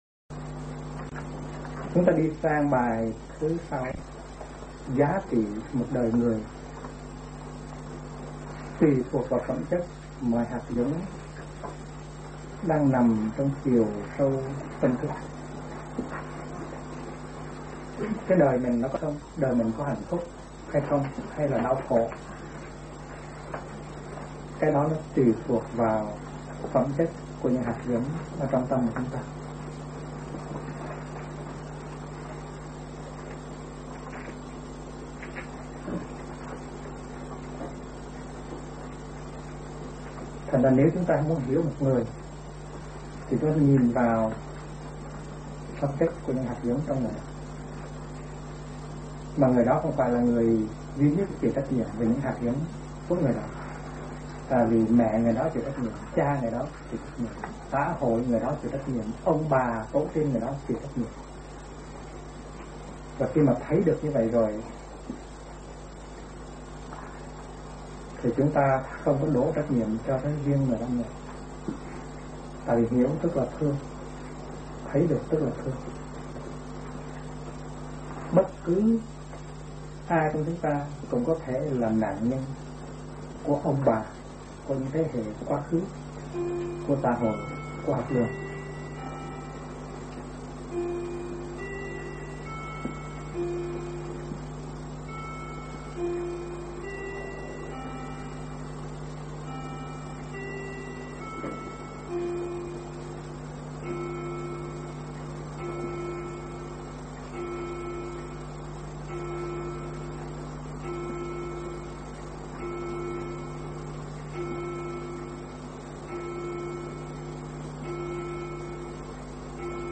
Mời quý phật tử nghe mp3 thuyết pháp Duy biểu học: Bài tụng số 06 do thầy Thích Nhất Hạnh giảng